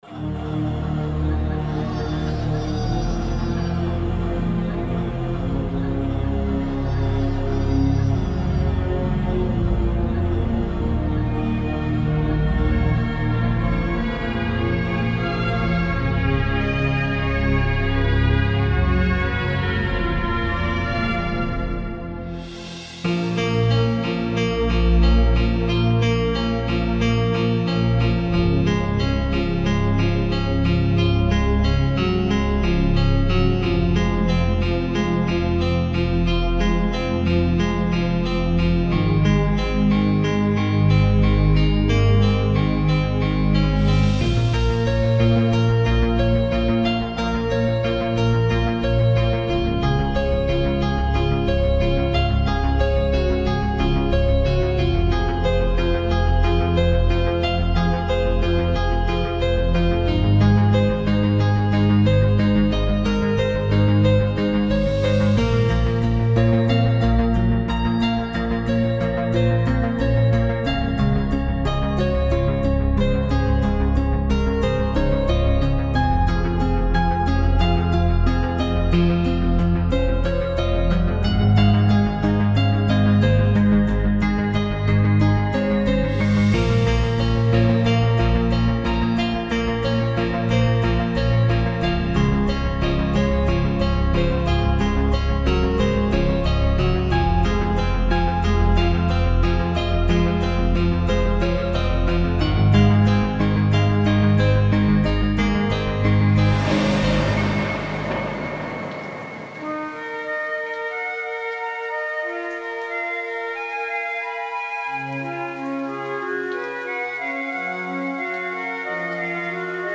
30.2 Долго вымучивал трек, в нем попытался совместить боль, переживание и надежду. Поэтому вставлены практически 2 разные темы.